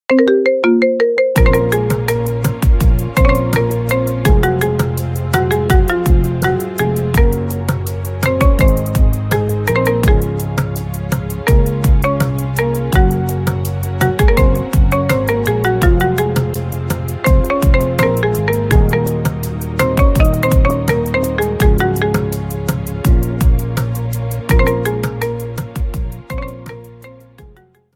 маримба
ремиксы